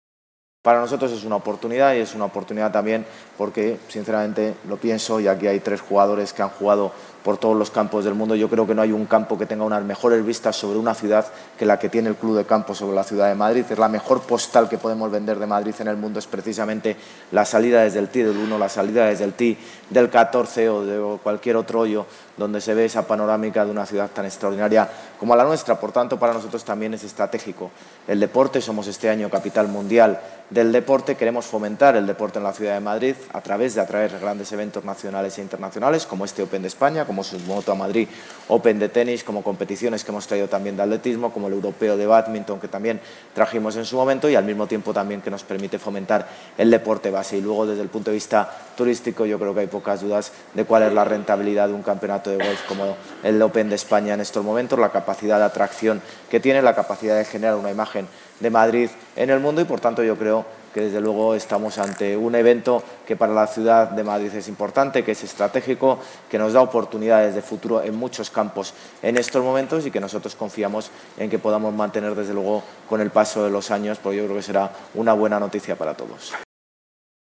Nueva ventana:Declaraciones del alcalde, José Luis Martínez-Almeida